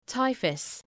Προφορά
{‘taıfəs}